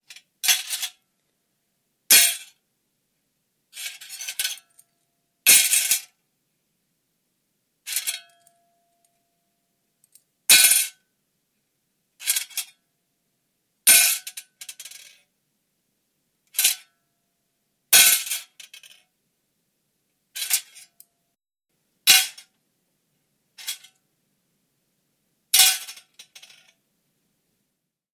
Звуки операции
Сигнал учащения пульса у пациента